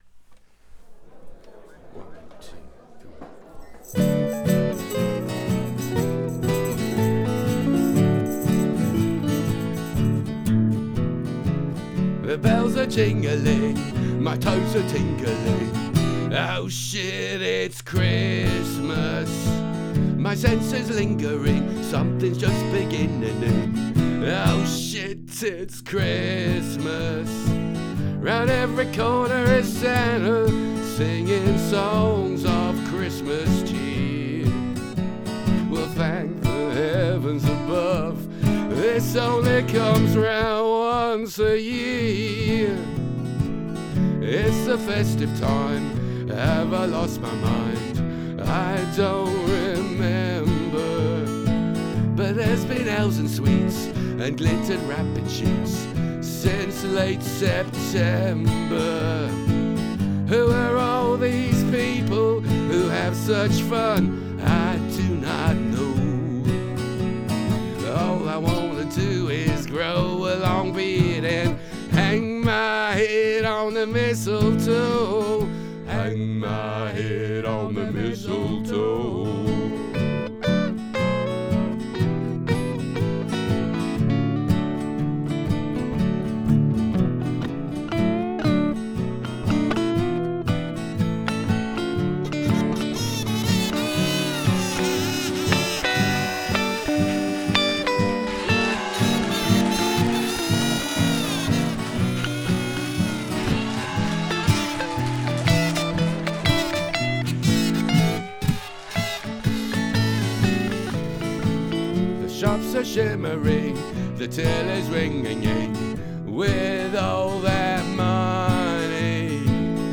UK Americana singer-songwriter